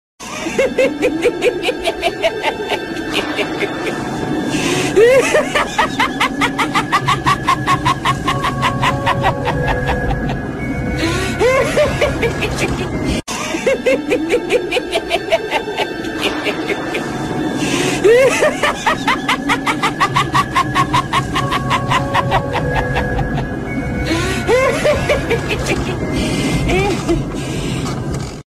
horror-laugh-ringtone_14154.mp3